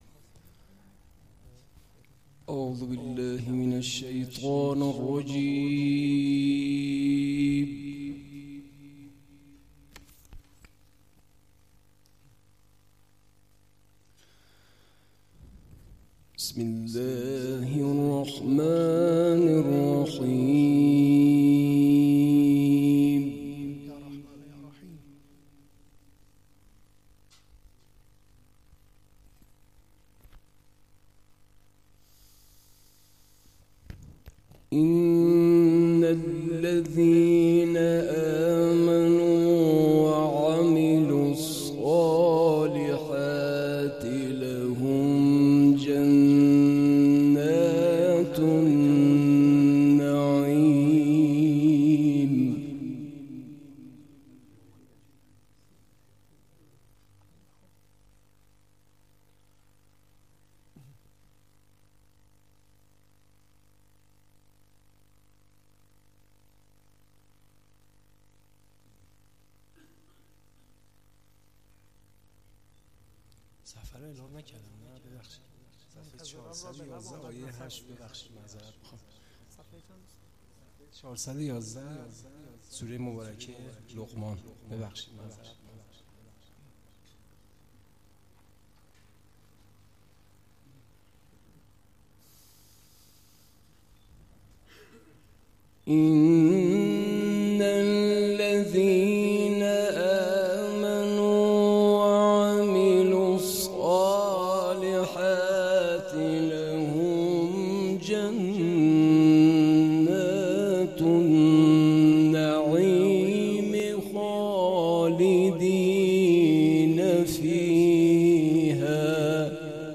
گروه جلسات و محافل: دومین جلسه مجمع قاریان محله سیزده آبان شهر ری، در مسجد حضرت ابوالفضل(ع) برگزار شد.